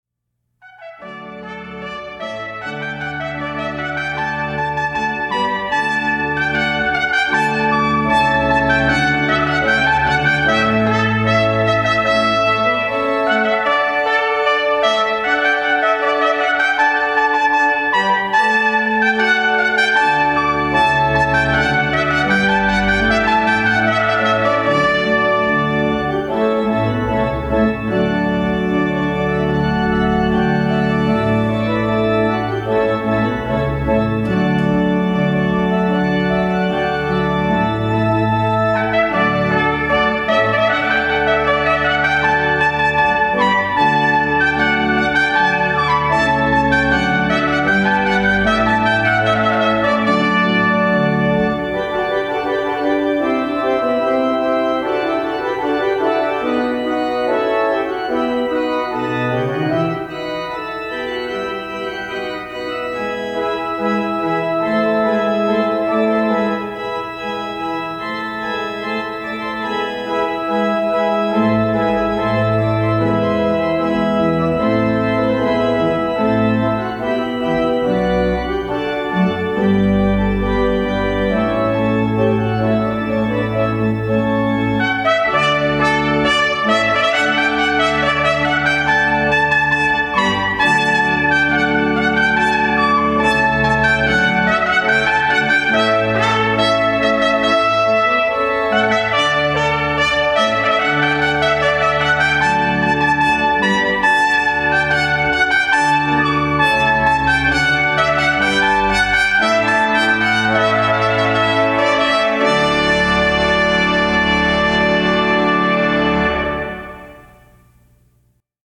live recordings of various clients
organ